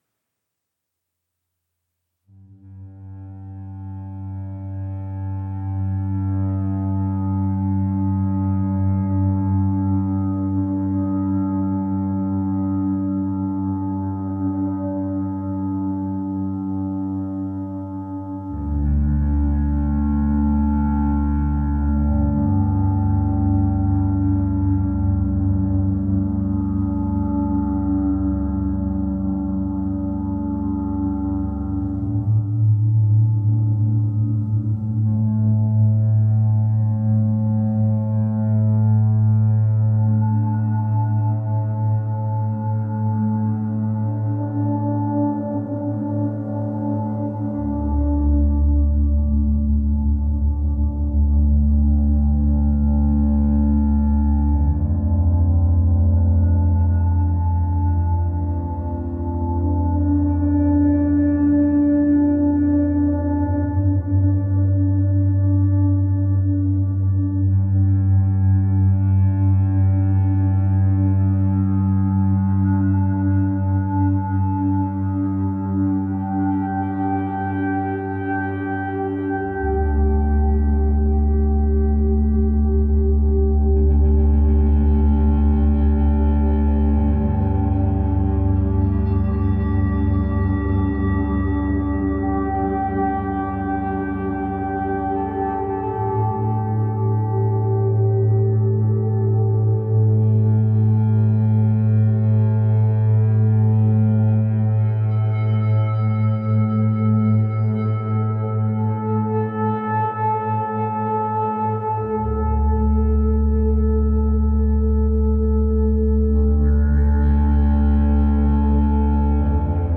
Not been around much… been spending time getting lost with a new modular setup :man_facepalming:t3:
Lovely resonances and modulations. Very calming.